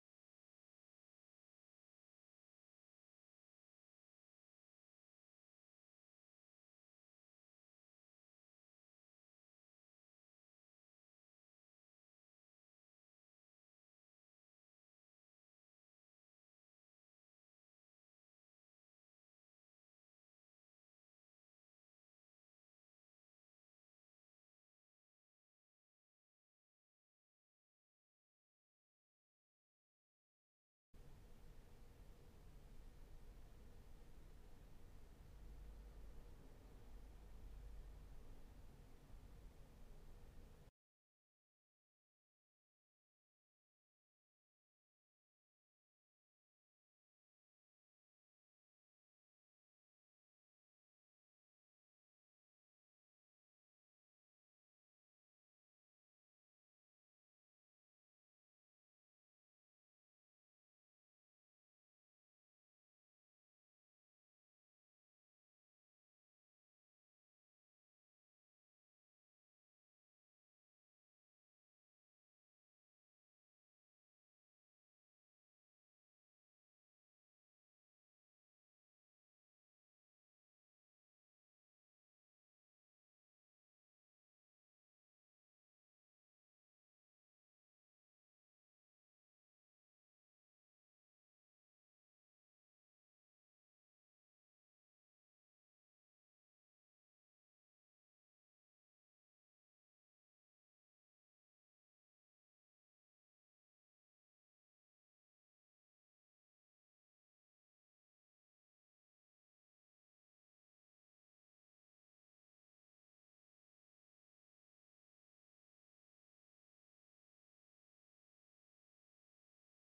台詞